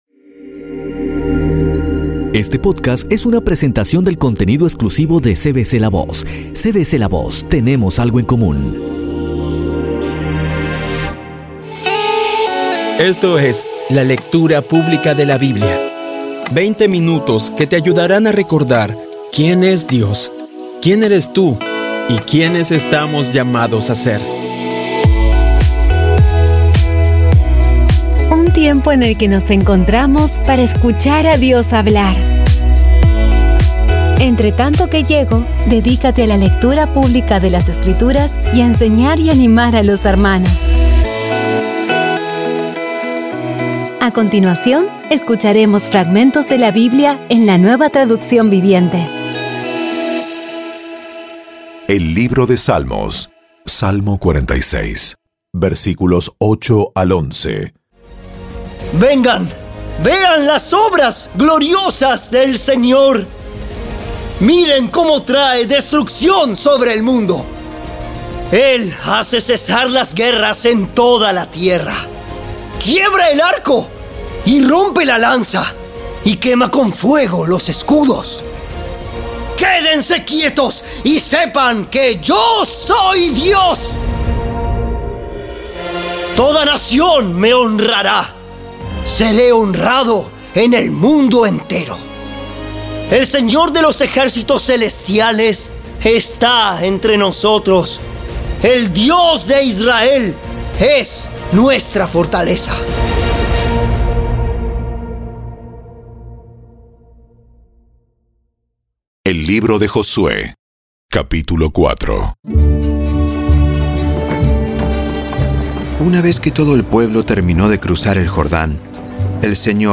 Audio Biblia Dramatizada Episodio 104
Poco a poco y con las maravillosas voces actuadas de los protagonistas vas degustando las palabras de esa guía que Dios nos dio.